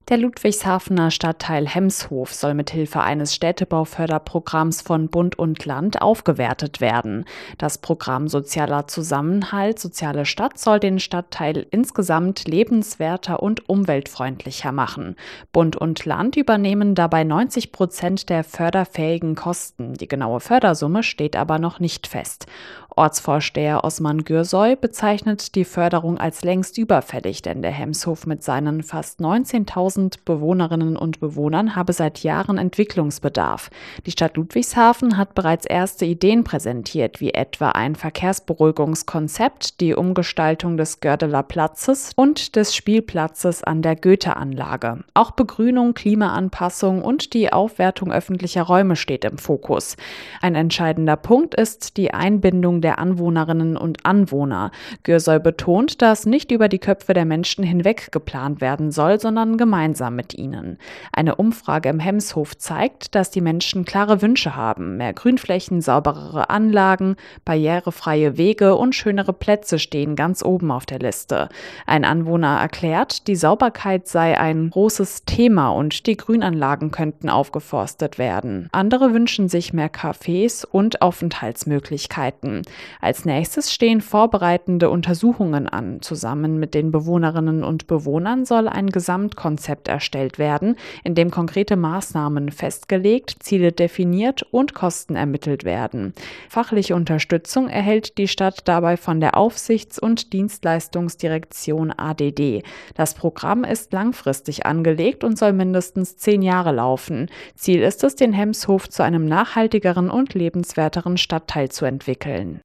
Wir haben Menschen vor Ort nach ihren Wünschen gefragt und auch mit der Stadt gesprochen.